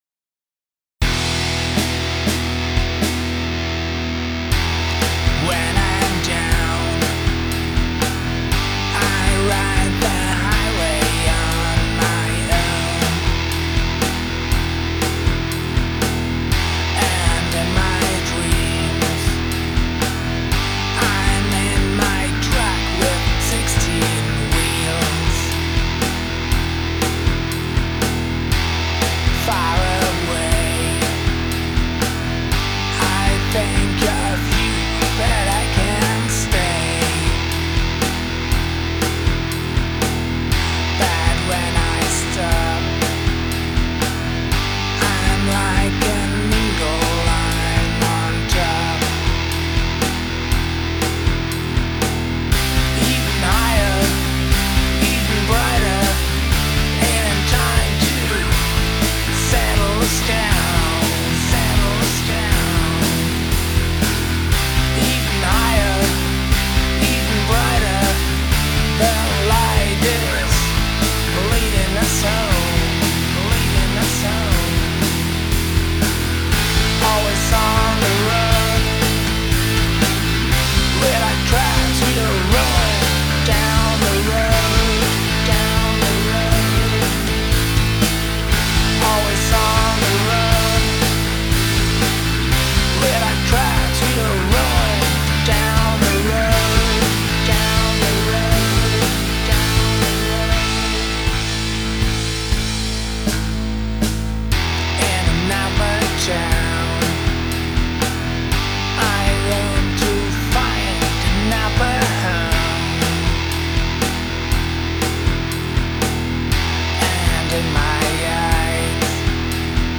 Virtuelles und kooperatives Rock-Projekt zum mitmachen
Ich spiele Gitarre, Bass und programmiere Schlagzeug. Aber alles weit vom Profiniveau entfernt.
Der Aufbau des Songs ist recht einfach: Strophe - Refrain - Strophe - Refrain -Gitarrensolo/Keybordsolo (noch nicht aufgenommen) Wer sollte sich angesprochen fühlen?
Bass: Ich habe beim Bass im Endeffekt das gleiche wie mit der Gitarre gemacht.